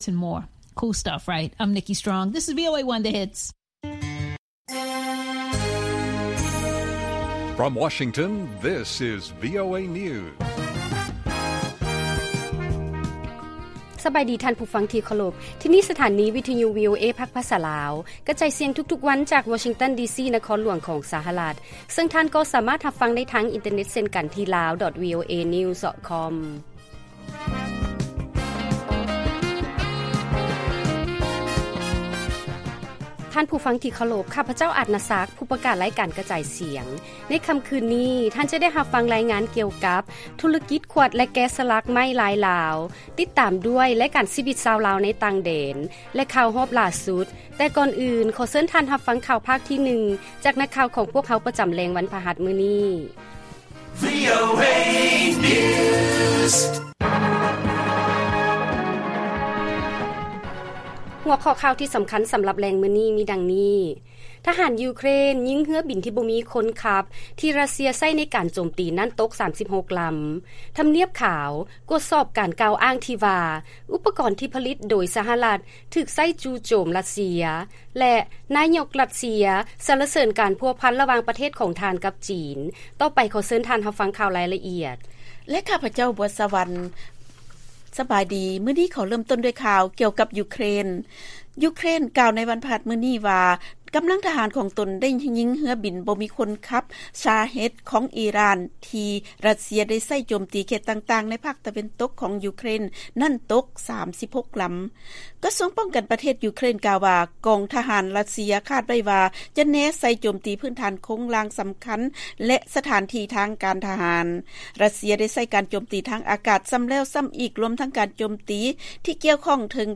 ວີໂອເອພາກພາສາລາວ ກະຈາຍສຽງທຸກໆວັນ, ຫົວຂໍ້ຂ່າວສໍາຄັນໃນມື້ນີ້ມີ: 1. ທະຫານຢູເຄຣນຍິງເຮືອບິນບໍ່ມີຄົນທີ່ ຣັດເຊຍໃຊ້ໃນການໂຈມຕີນັ້ນ ຕົກ 36 ລຳ, 2. ທຳນຽບຂາວກວດສອບການກ່າວອ້າງທີ່ເວົ້າວ່າ ອຸປະກອນທີ່ຜະລິດໂດຍ ສະຫະລັດຖືກໃຊ້ຈູ່ໂຈມຣັດເຊຍ, ແລະ 3. ນາຍົກຣັດເຊຍ ສັນລະເສີນ ການພົວພັນ ລະຫວ່າງປະເທດ ຂອງທ່ານ ກັບຈີນ.